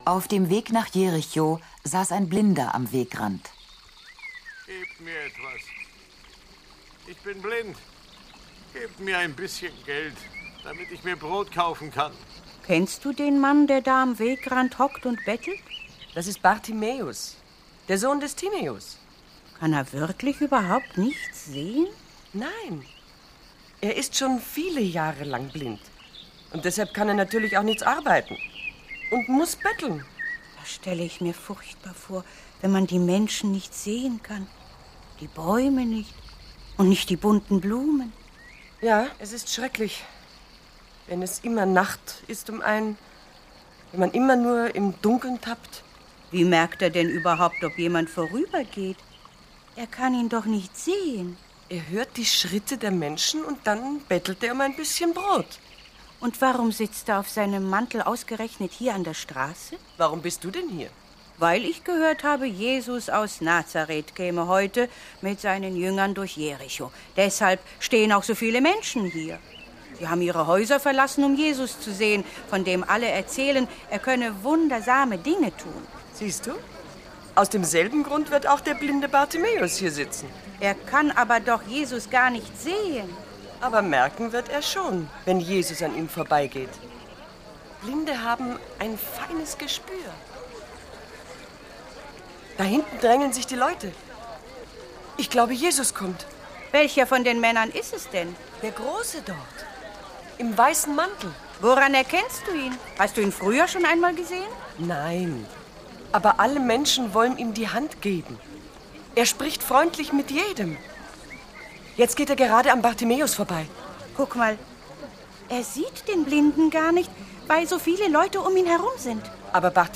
Aber Bartimäus ist fest entschlossen, ergreift seine Chance und schreit so laut, dass Jesus ihn hört... Die Heilung des blinden Bartimäus als Hörgeschichte!